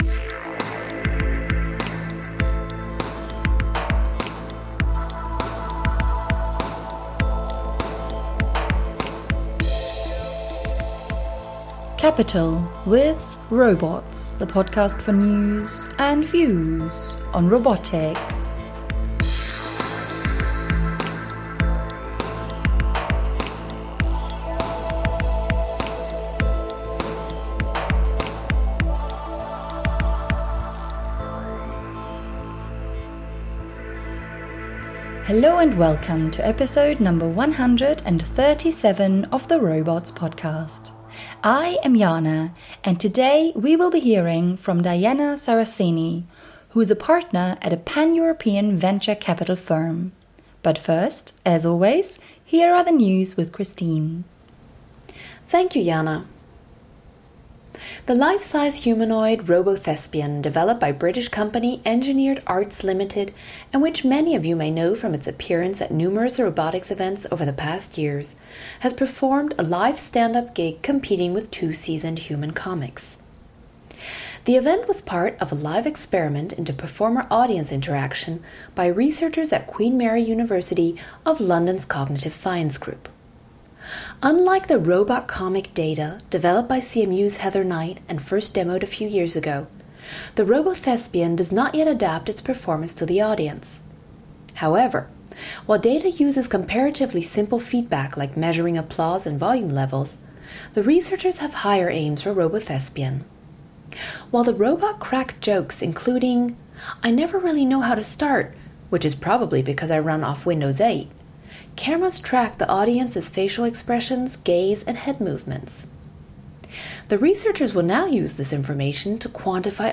In this interview, she tells us about her first investment in robotics 3 years ago with Invendo Medical, and her views on how the market has changed since then. Hardware is now perceived as less risky, even though it is more challenging to scale than software. Recent success stories have further helped promote VC funding in robotics.